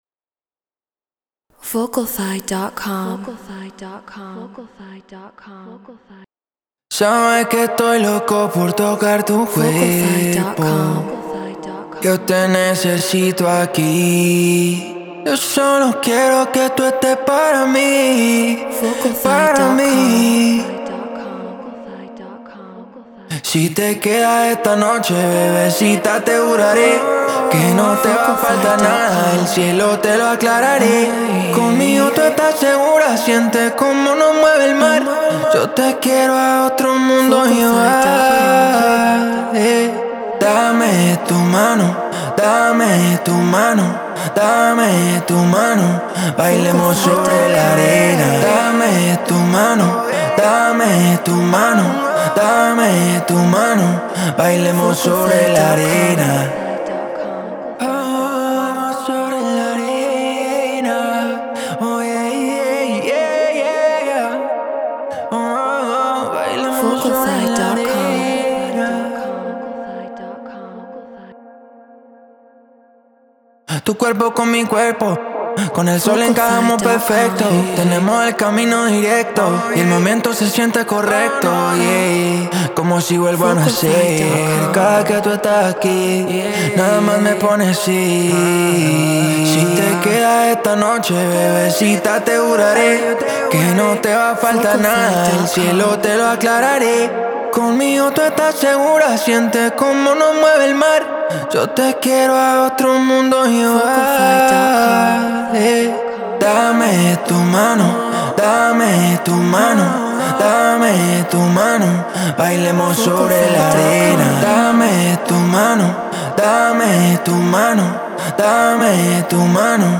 Afro House 120 BPM A#min
Human-Made
Treated Room